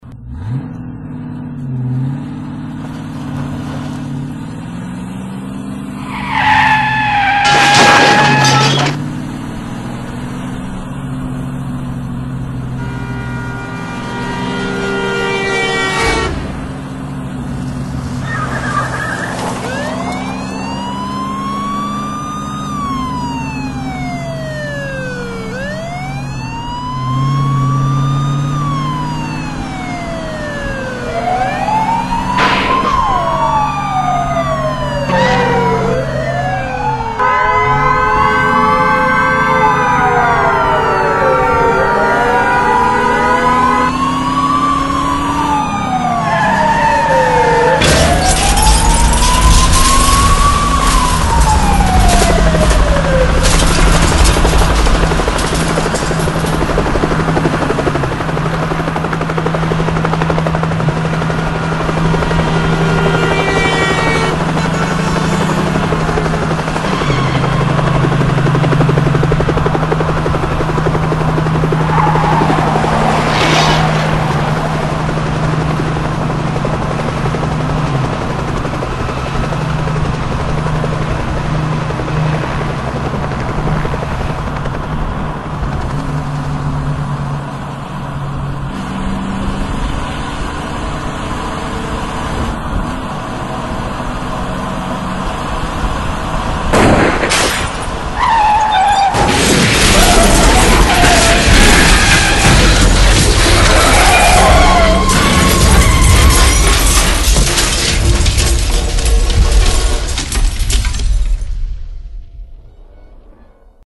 Автомобильная погоня с воем сирен